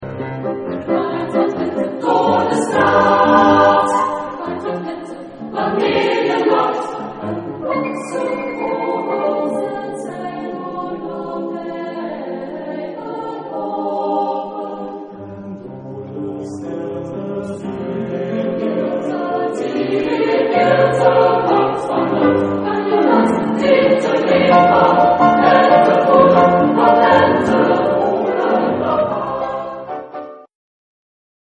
Genre-Stil-Form: Liedsatz ; weltlich ; zeitgenössisch
Chorgattung: SATB  (4 gemischter Chor Stimmen )
Instrumente: Klavier (1)
Tonart(en): C-Dur